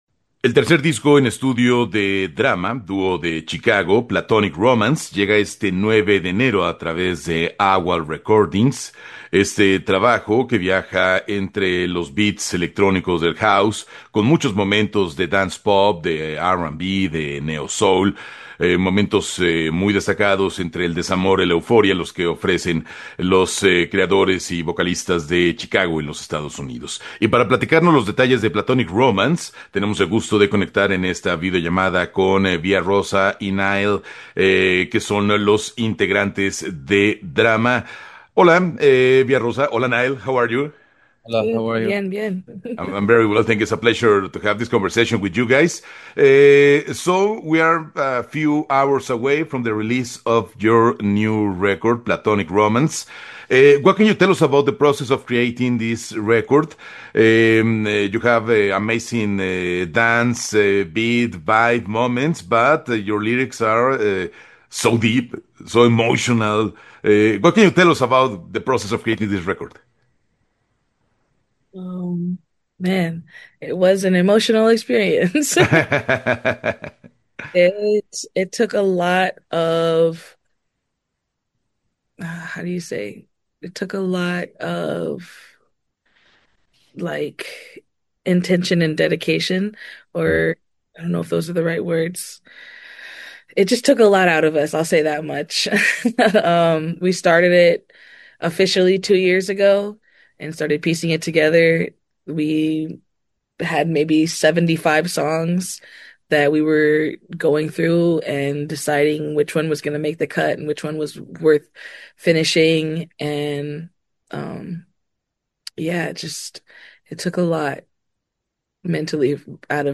Entrevista-DRAMA-2026.mp3